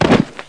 GolpeSuelo.mp3